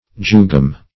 Meaning of jugum. jugum synonyms, pronunciation, spelling and more from Free Dictionary.